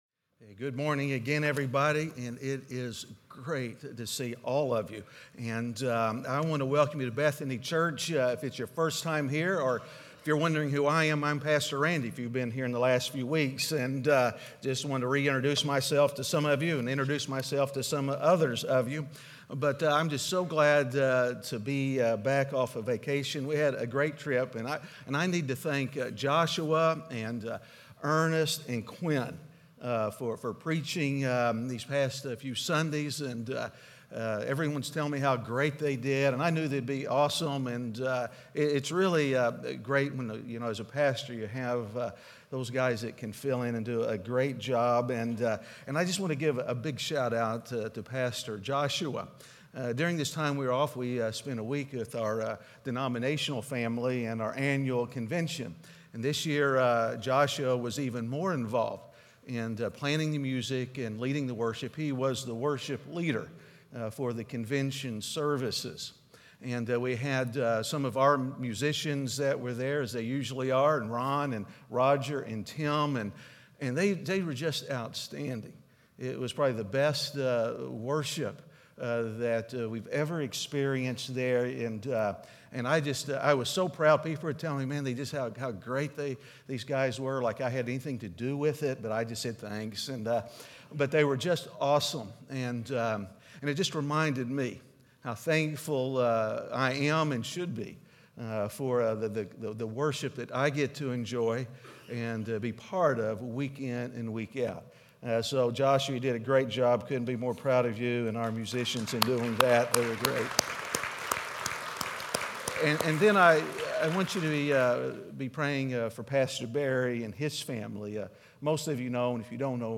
A message from the series "Parables."